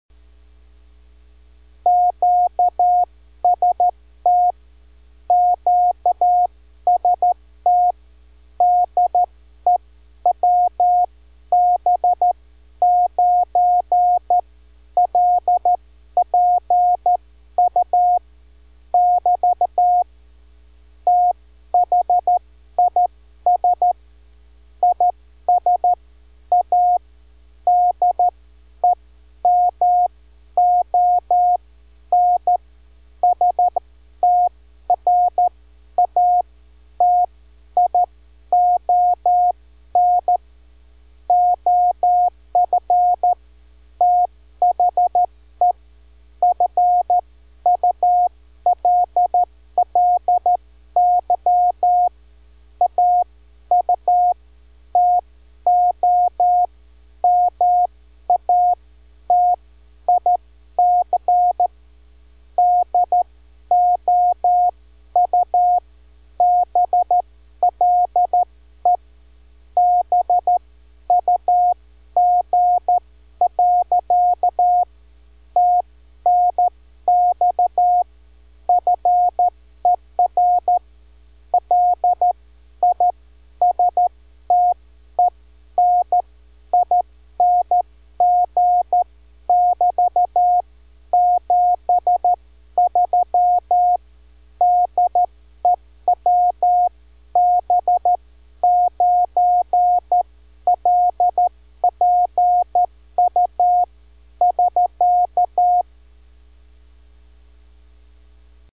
Audio Demonstration of one of the Parkwood Double Bugs in Action: